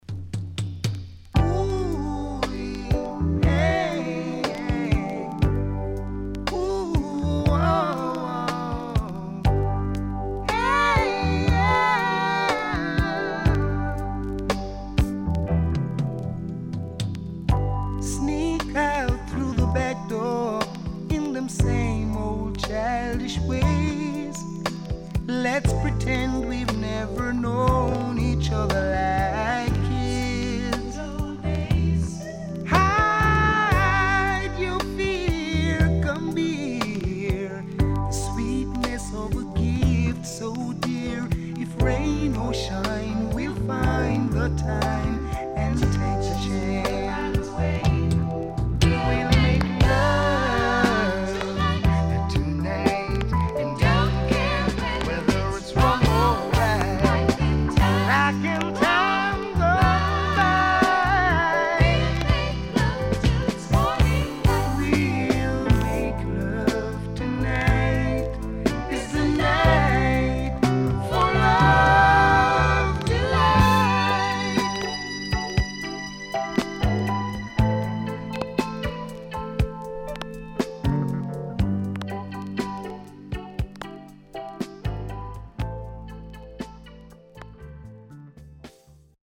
SIDE A:少しノイズあり、A-3傷によるパチノイズ入ります。